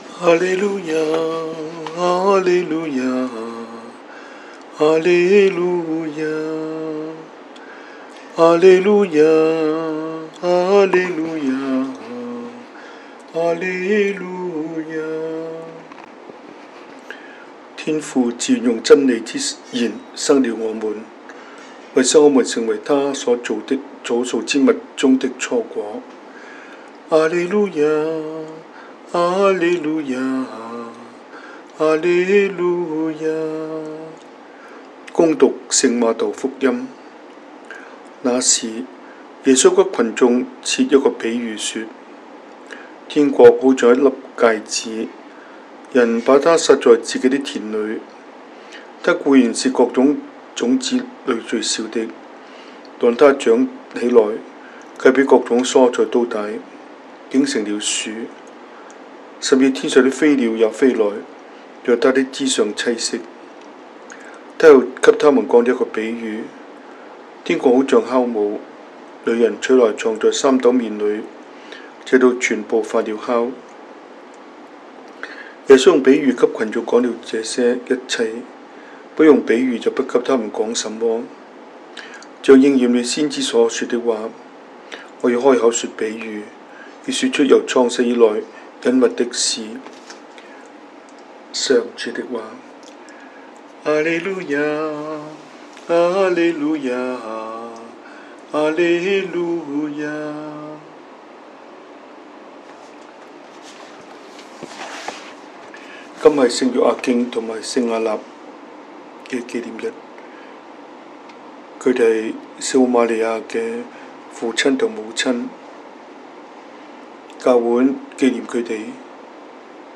中文講道,